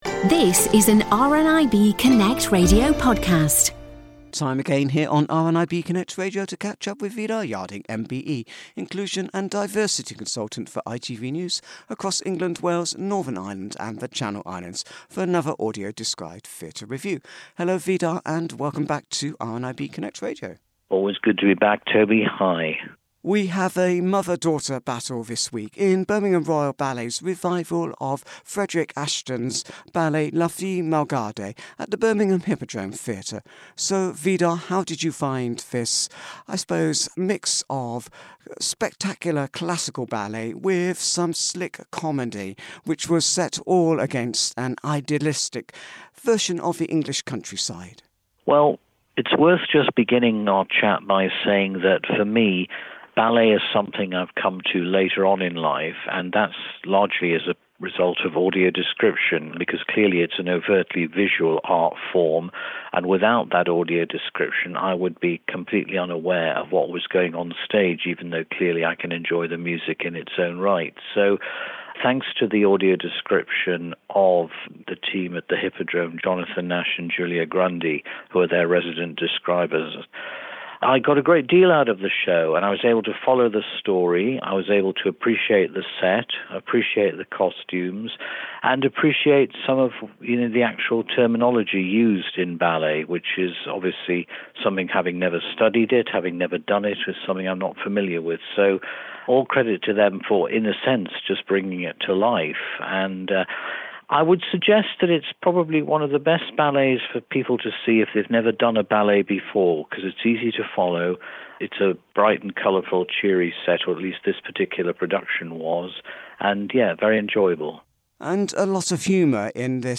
AD Theatre Review